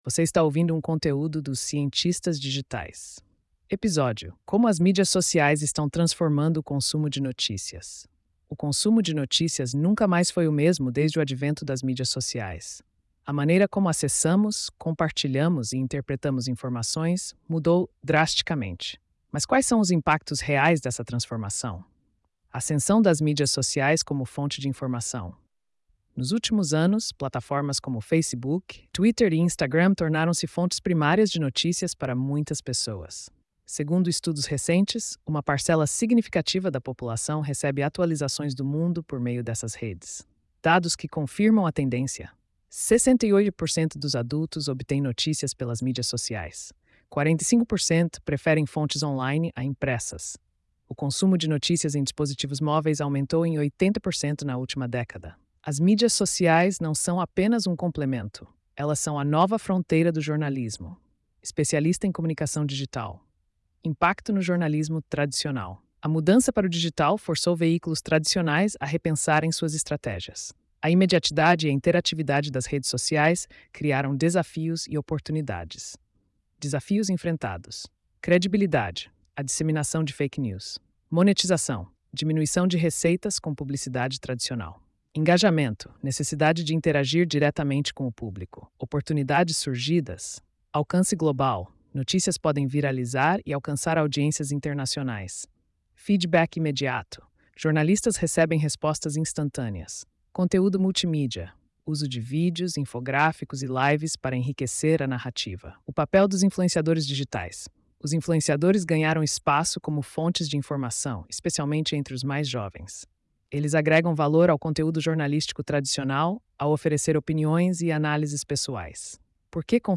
post-2525-tts.mp3